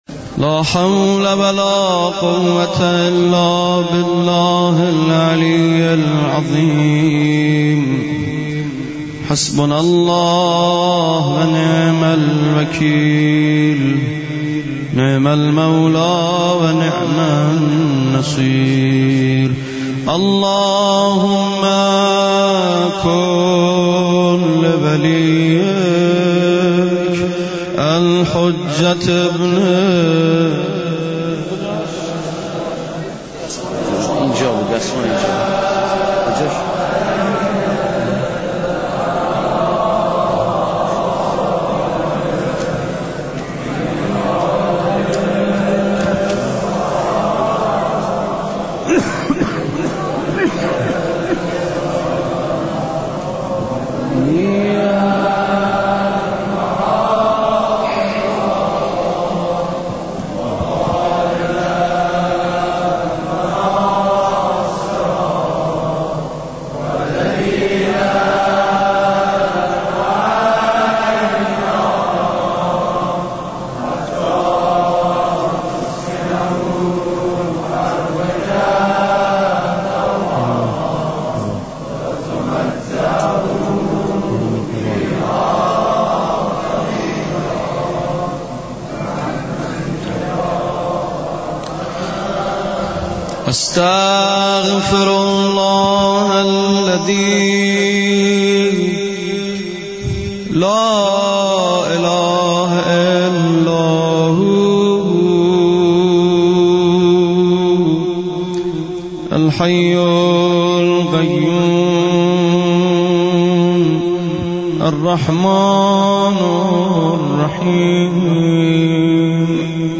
مراسم مناجات با خدا در حسینیه ام البنین(س) اهواز دانلود